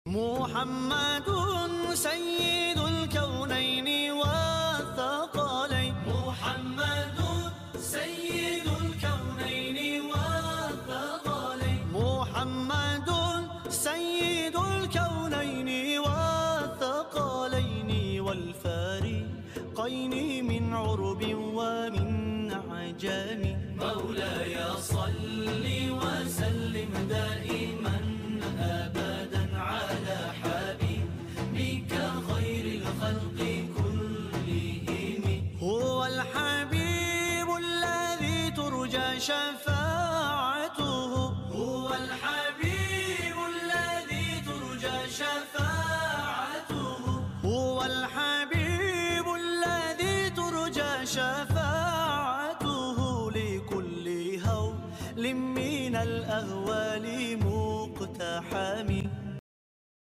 halalmusic